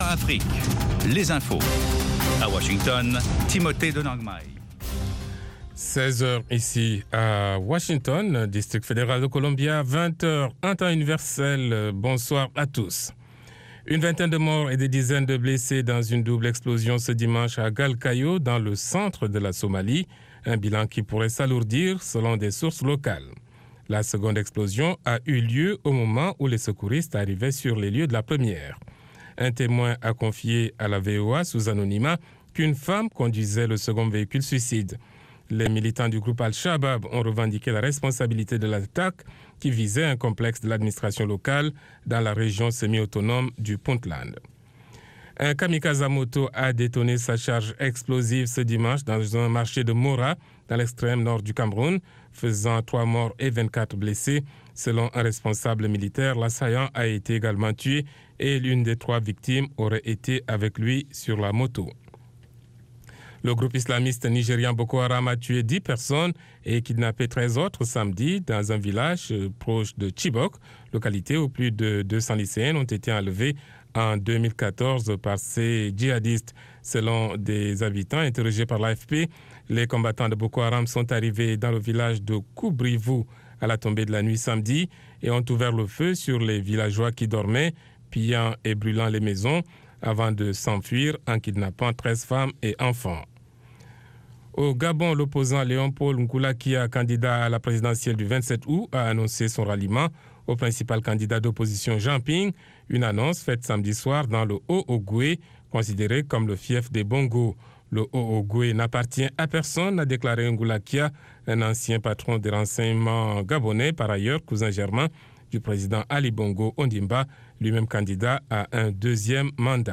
RM Show - French du blues au jazz